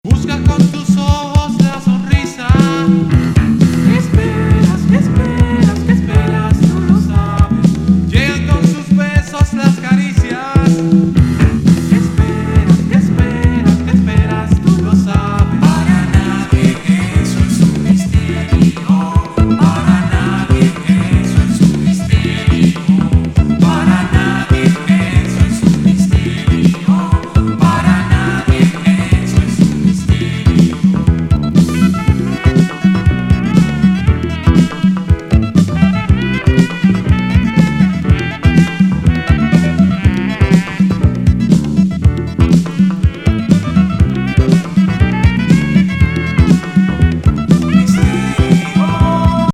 キューバ産アーバンAORグループ
スムース＆メロウな
極上オブスキュア・バレアリック